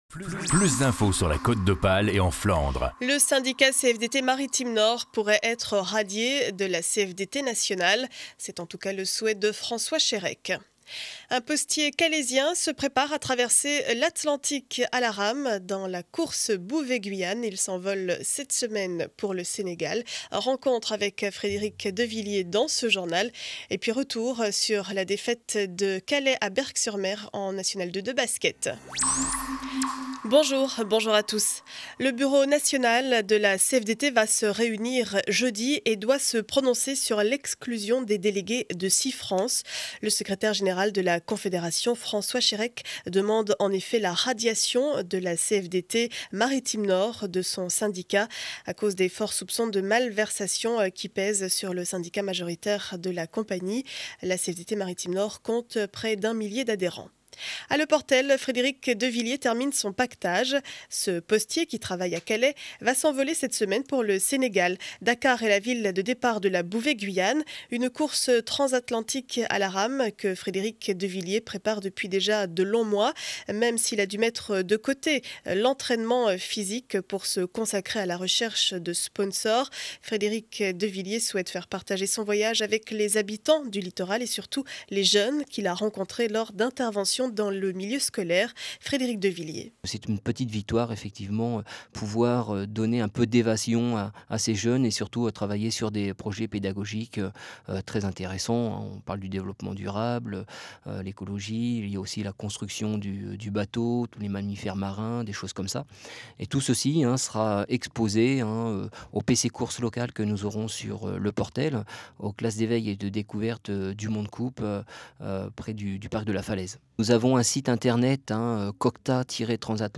Journal du lundi 16 janvier 2012 7 heures 30 édition du Calaisis.